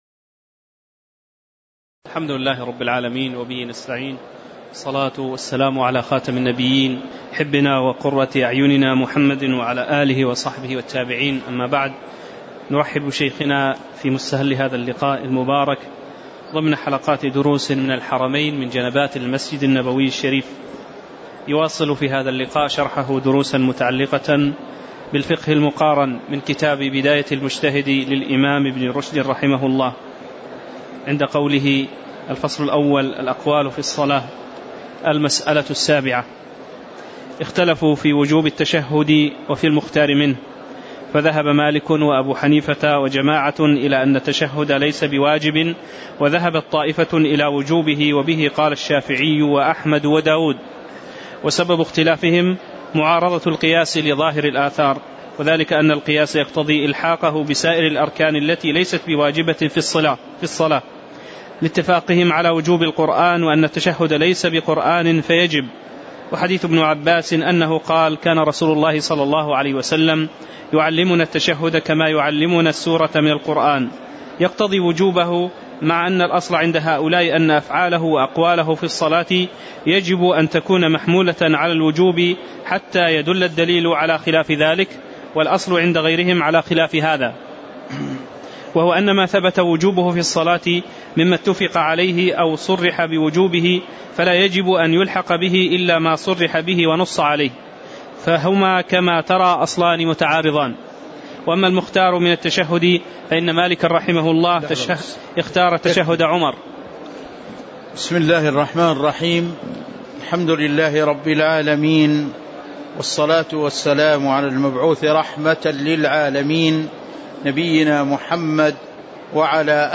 تاريخ النشر ١٢ ربيع الثاني ١٤٤١ هـ المكان: المسجد النبوي الشيخ